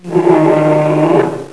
bear_polar.wav